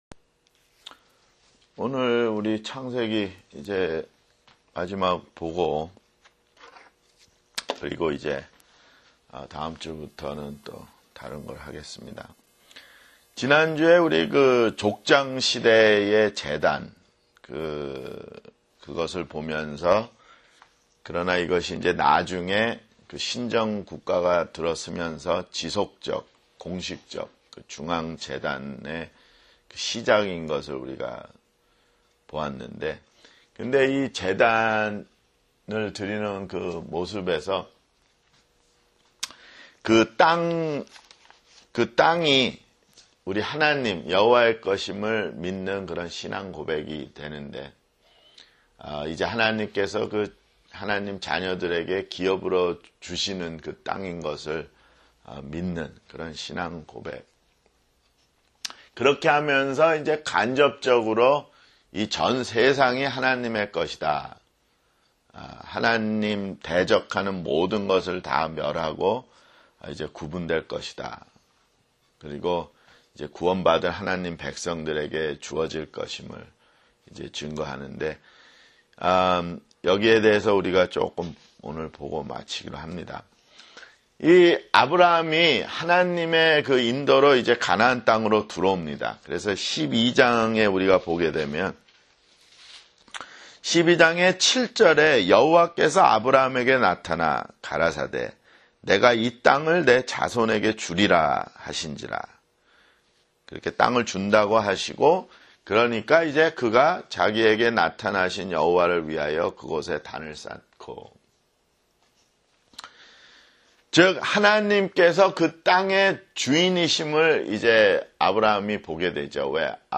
[성경공부] 창세기 (53)